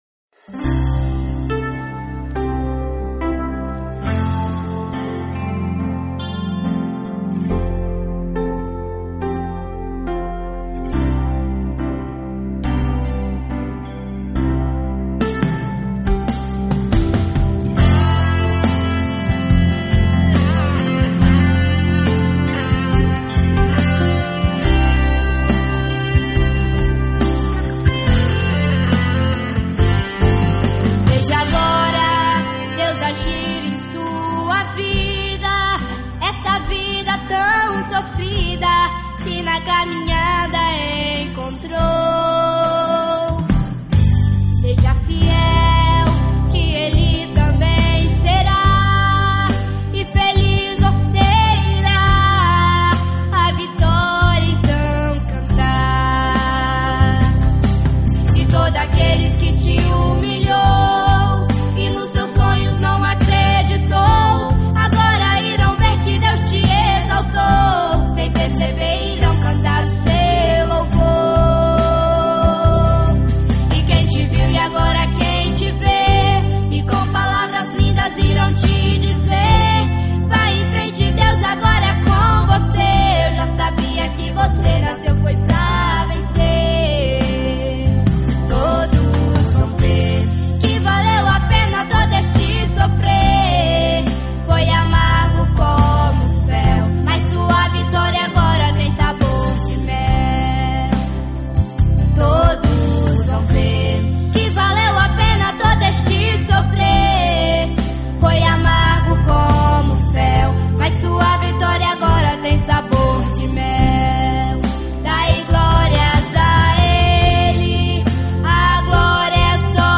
Sertanejo gospel